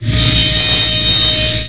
sawblade_on.wav